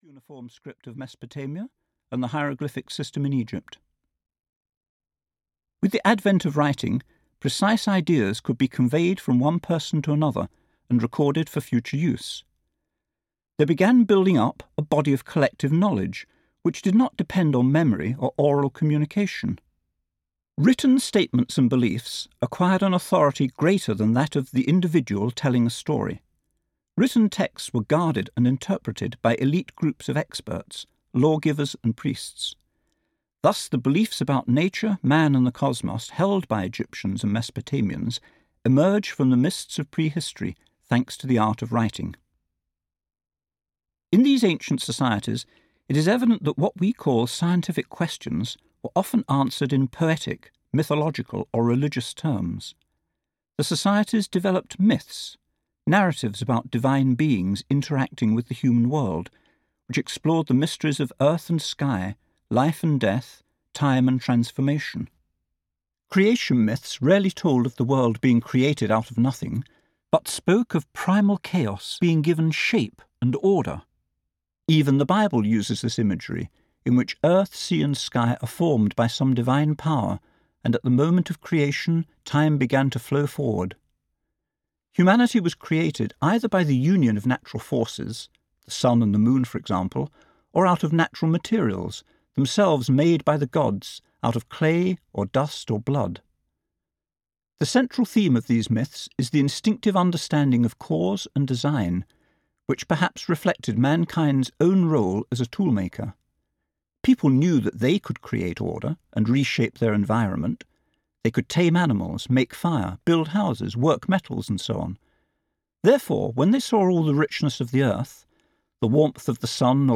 The History of Science (EN) audiokniha
Ukázka z knihy